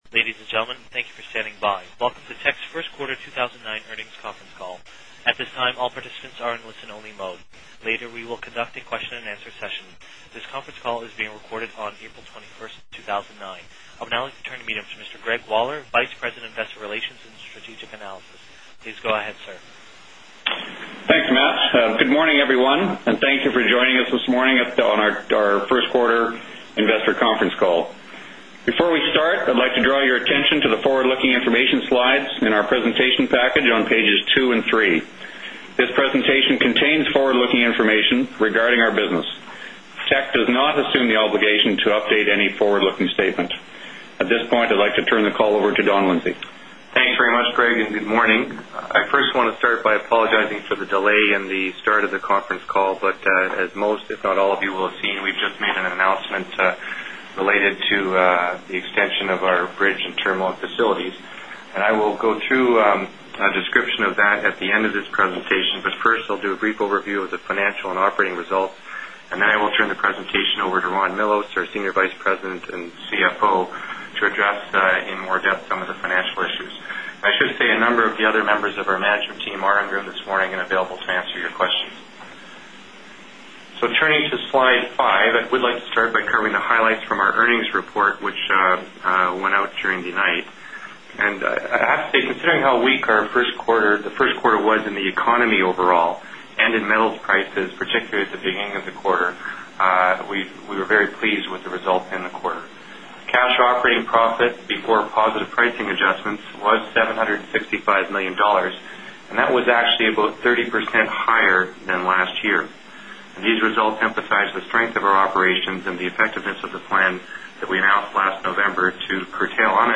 Q1 2009 Financial Report Conference Call